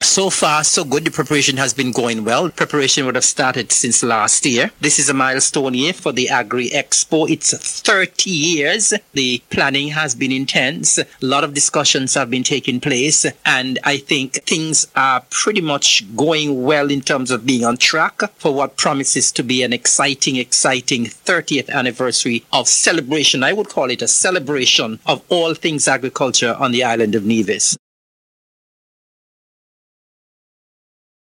Deputy Premier and Minister of Agriculture-Nevis, the Hon. Eric Evelyn, spoke of how preparations are going: